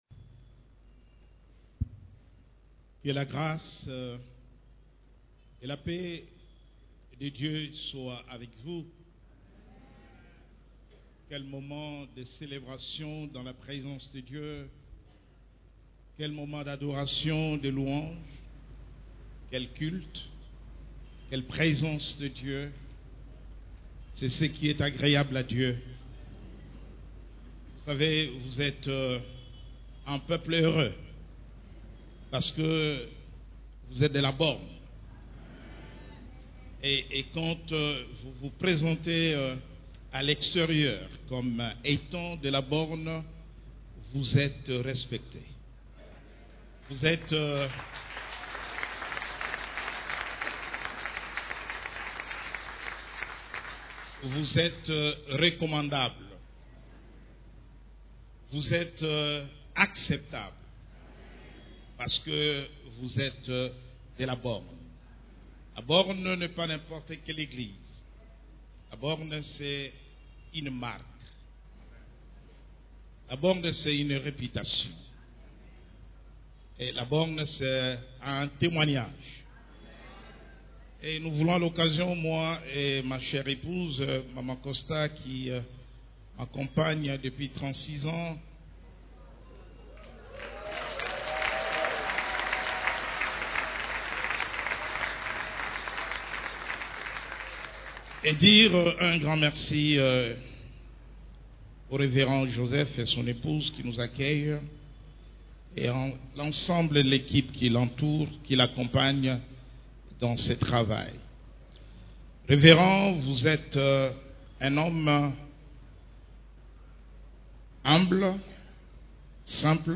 CEF la Borne, Culte du Dimanche, Comment être libéré du non pardon ?